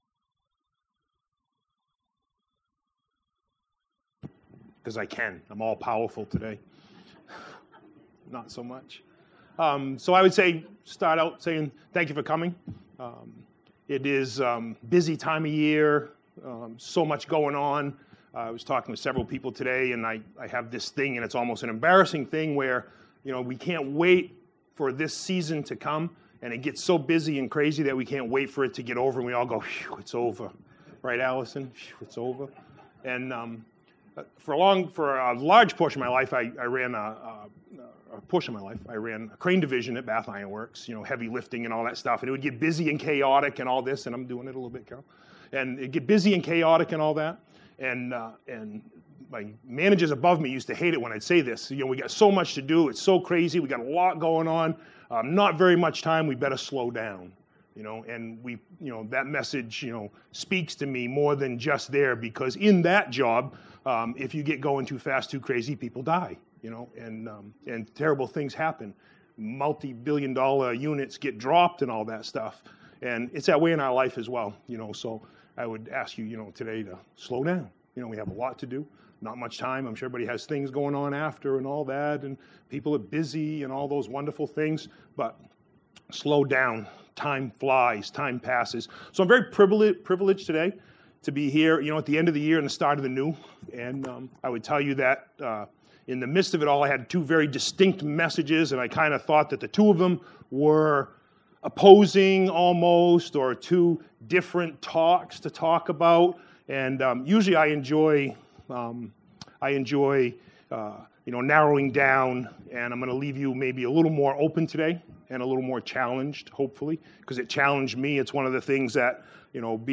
Shiloh Chapel